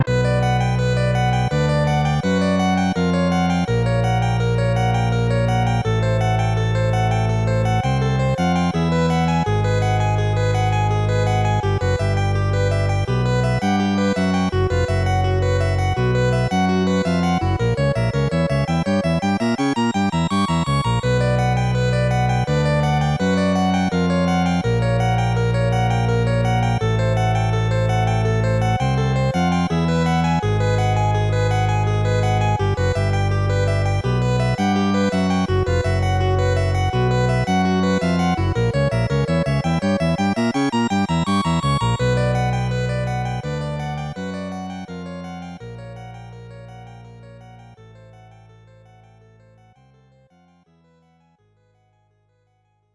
This file is an audio rip from a(n) SNES game.